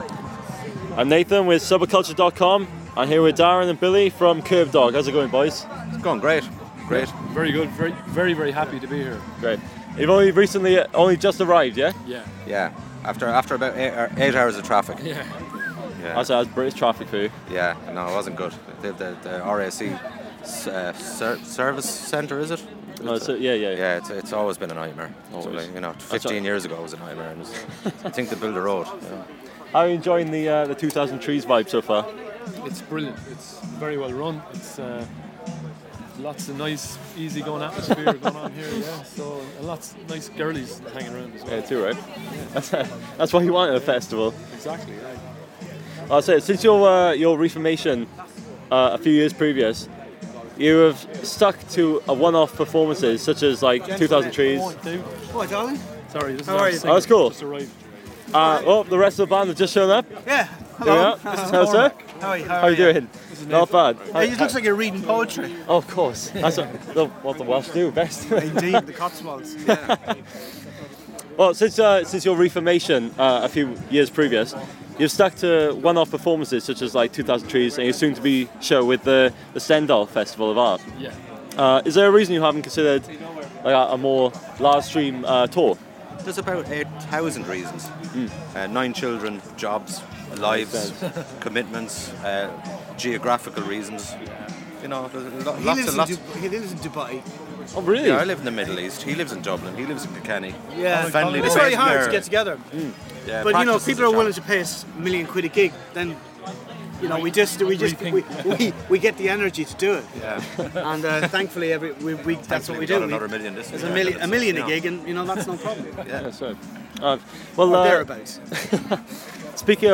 Kerbdog's interview with Subba-Cultcha at 2000 trees festival 2015